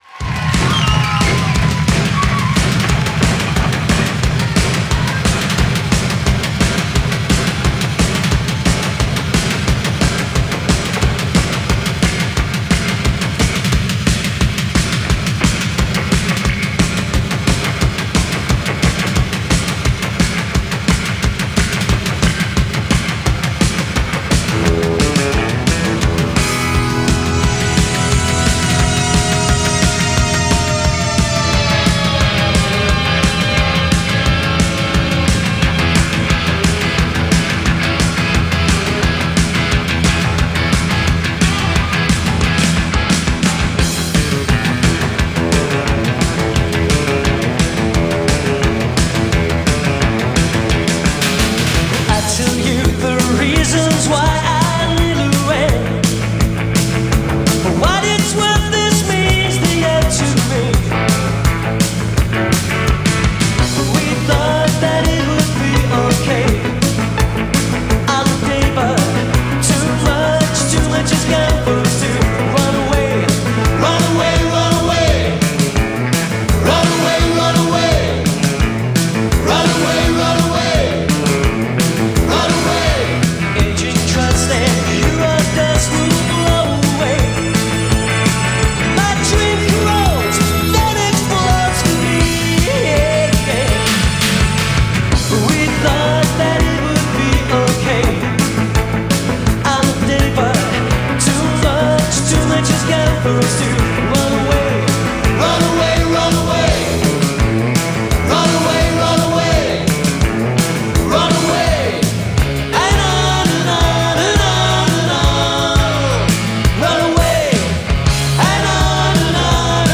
Recorded at The Paris Theatre, London
piano